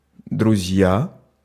Ääntäminen
US : IPA : /fɹɛn(d)z/